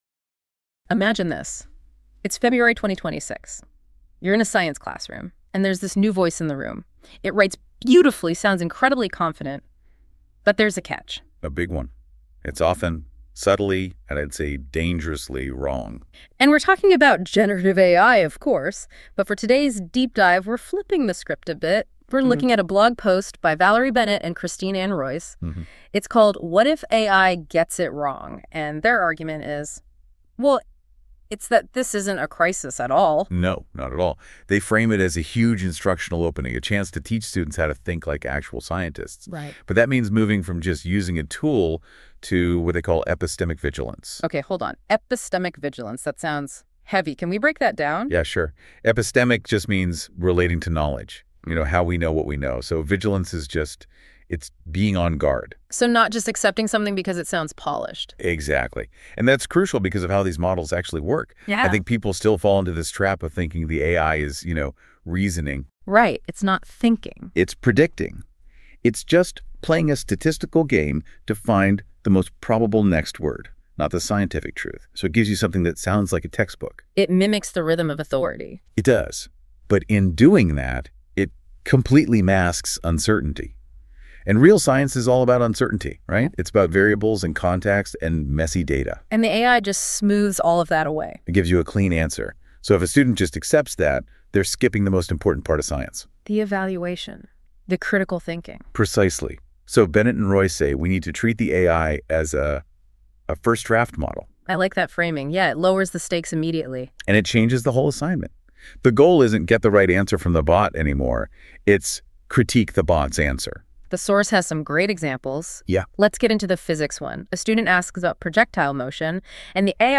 The following video and audio synopsis of this blog were generated using Google NotebookLM's features.